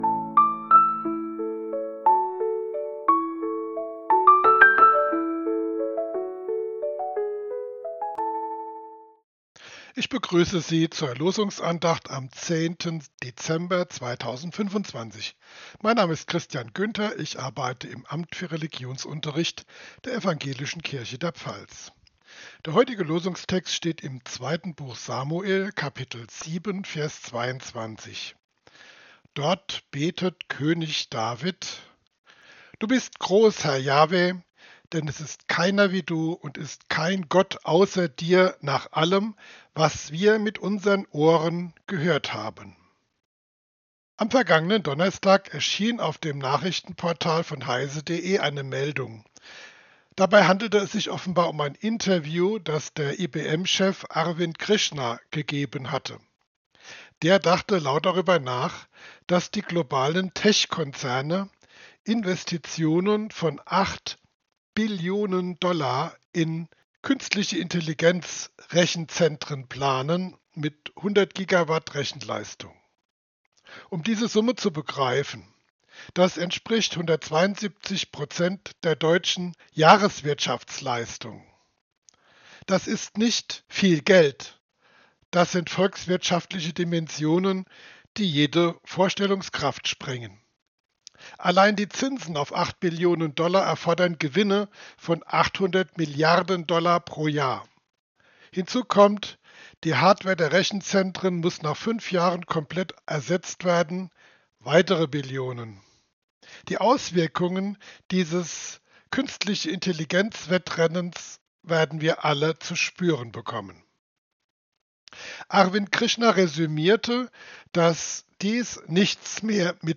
Losungsandacht für Mittwoch, 10.12.2025
Losungsandachten